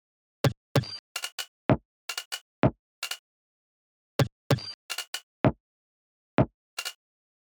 Night_128 – Stack_Perc_1
bass house construction kit drops